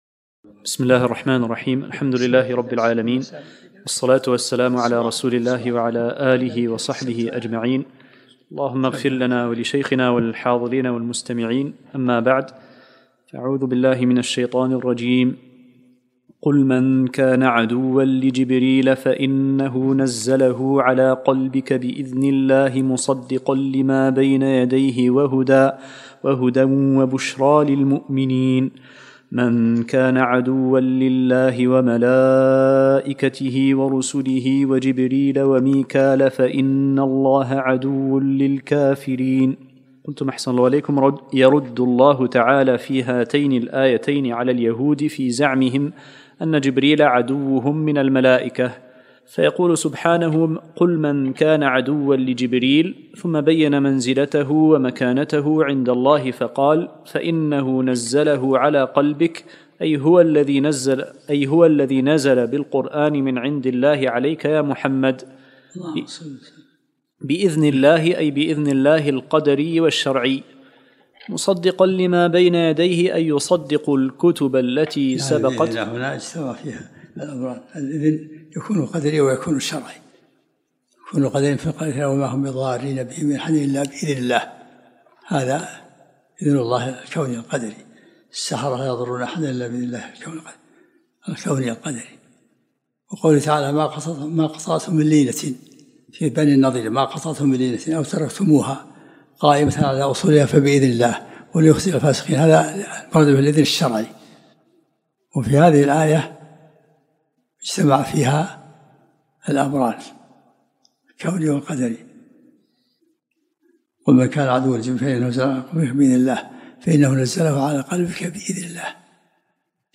الدرس السابع من سورة البقرة